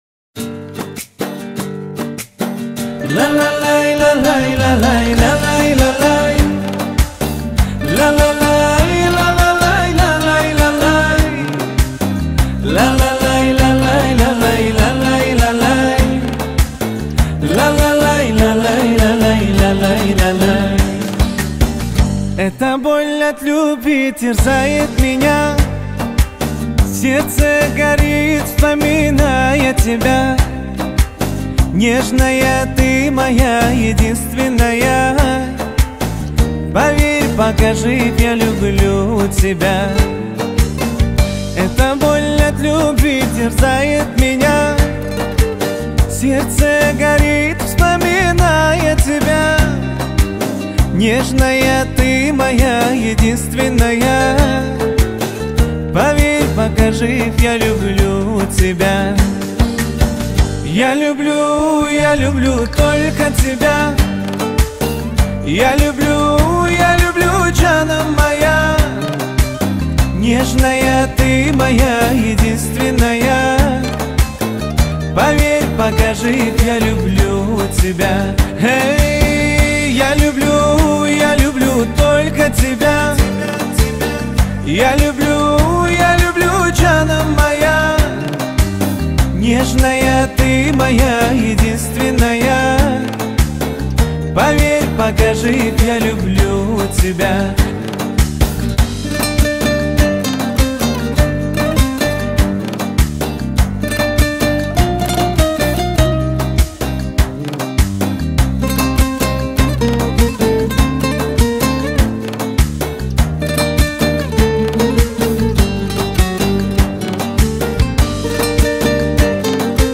Качество: 320 kbps, stereo
Узбекская музыка